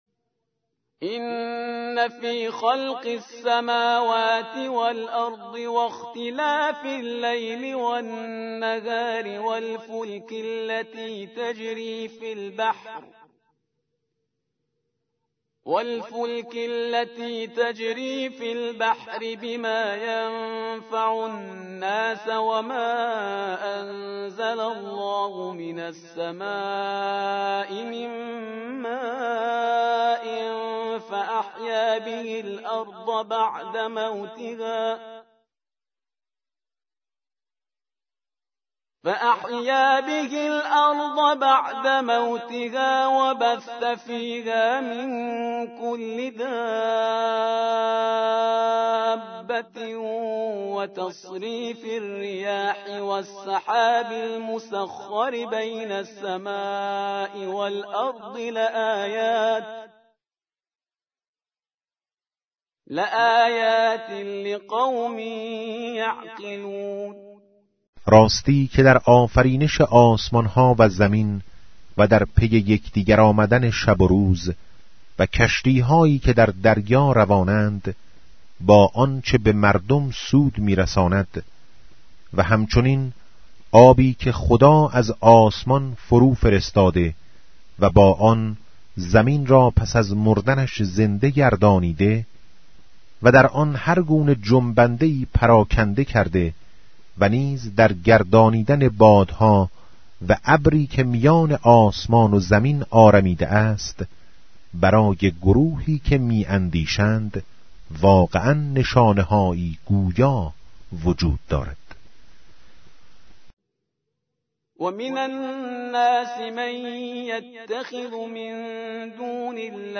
سوره 《بقره》 🔉 صوت قرائت این صفحه، در زیر همین پست، قابل دریافت است.